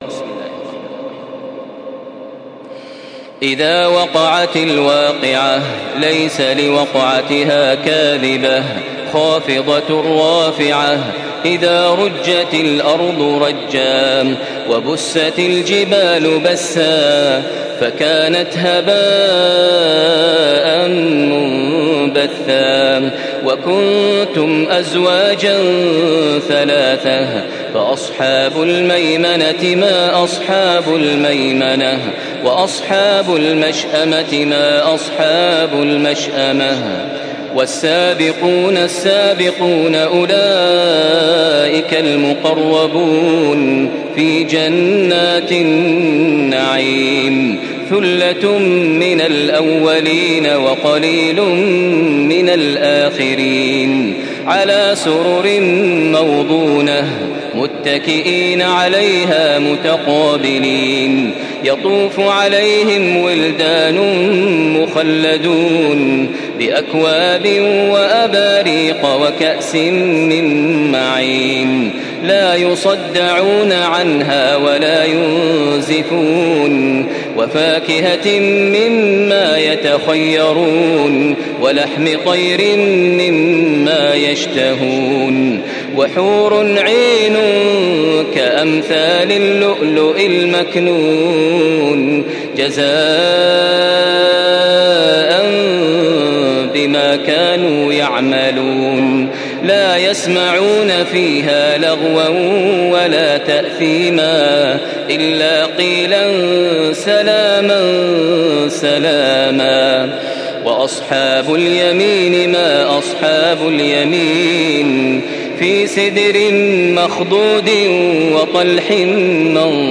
تحميل سورة الواقعة بصوت تراويح الحرم المكي 1435
مرتل